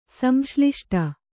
♪ samśliṣṭa